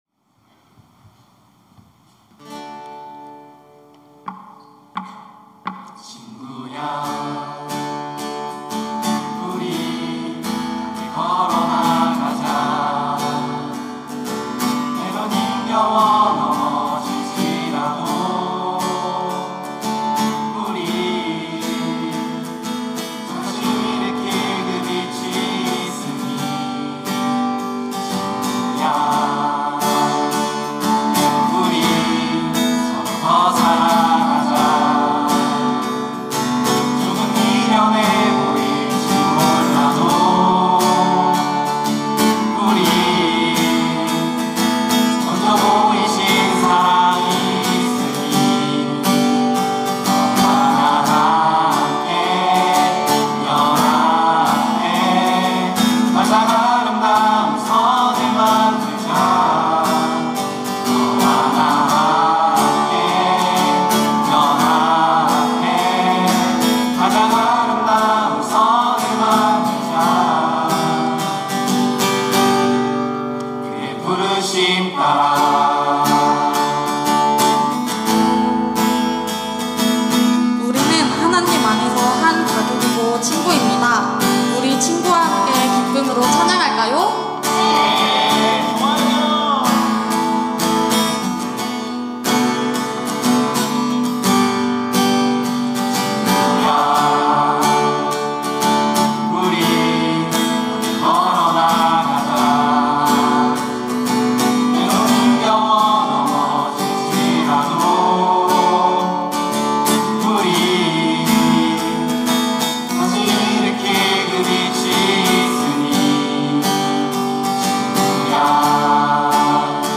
특송과 특주 - 친구야
청년부 27기